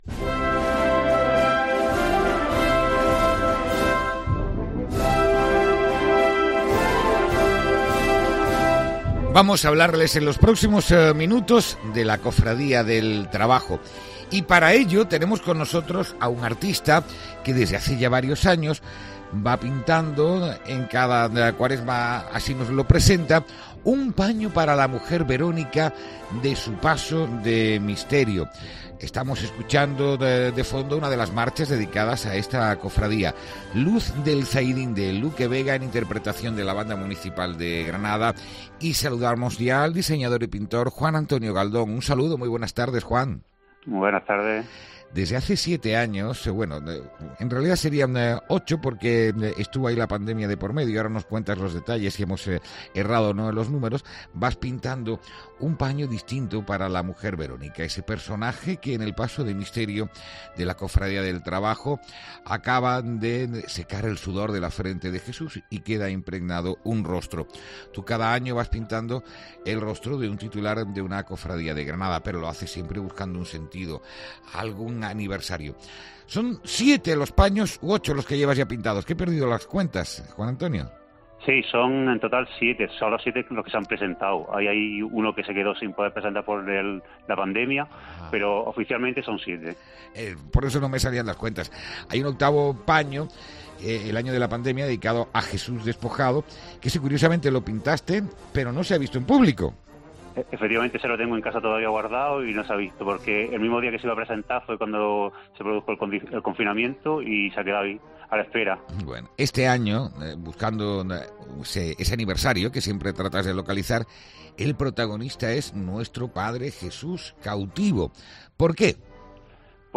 ENTREVISTA|| La Verónica de la Cofradía del Trabajo estrenará un paño